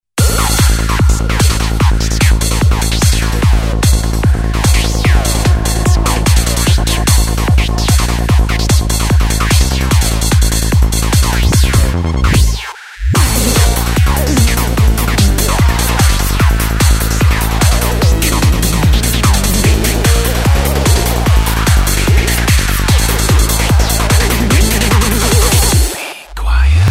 /128kbps) Описание: Транс просто бомба!